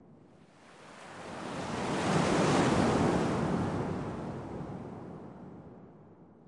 Sports Wave Whoosh
Arena Crowd; Wave Whoosh For Crowd Transition.